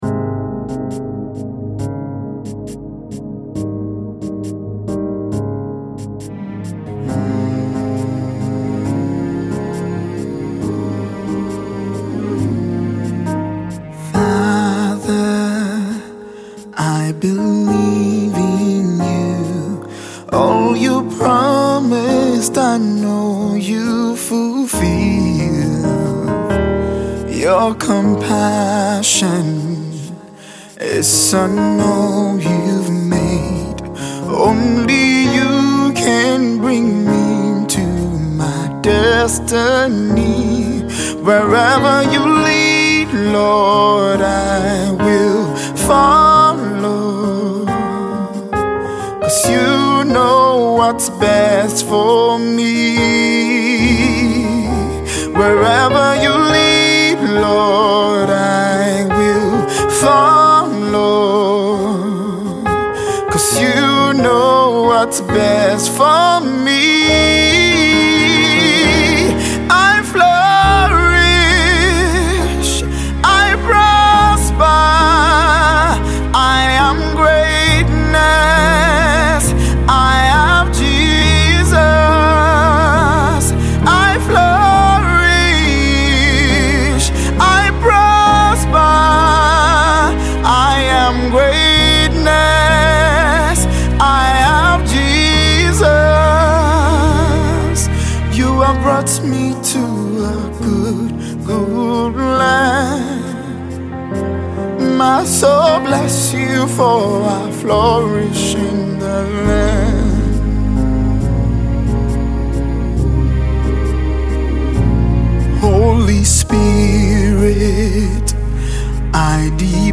a song of prayer with strong positive words of declaration